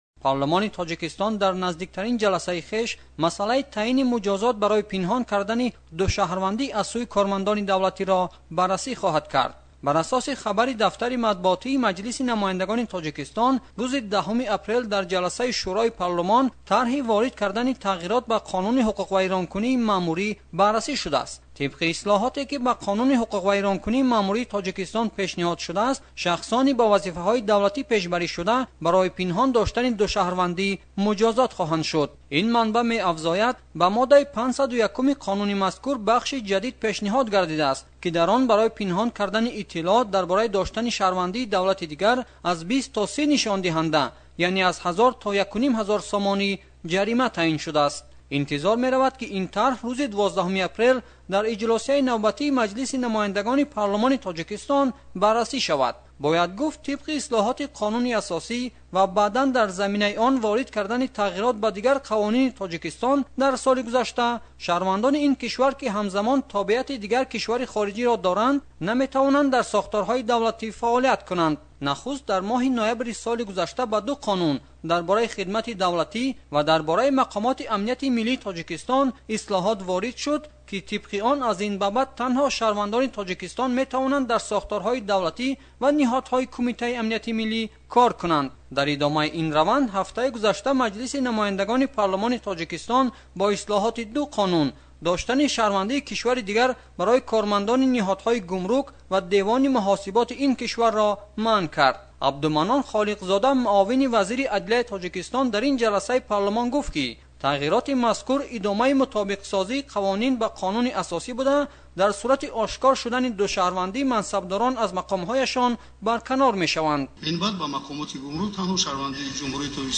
Гузориши ҳамкорамон